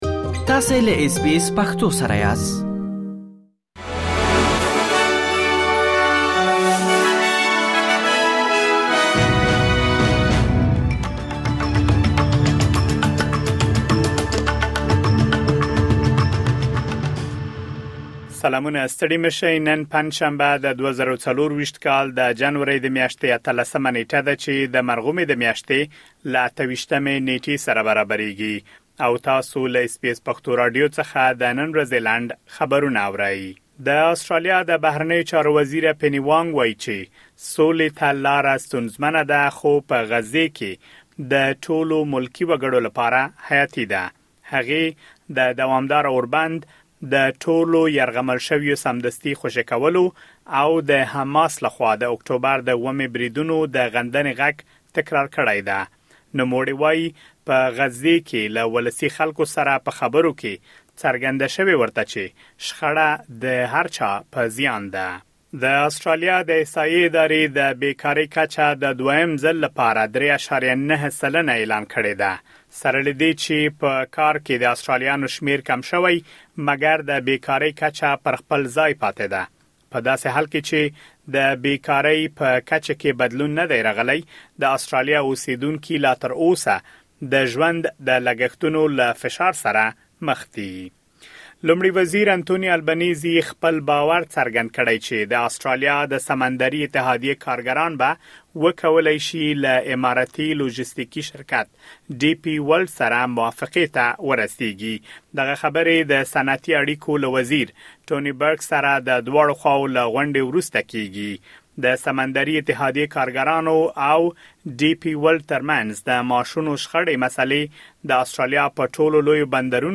د اس بي اس پښتو راډیو د نن ورځې لنډ خبرونه | ۱۸ جنوري ۲۰۲۴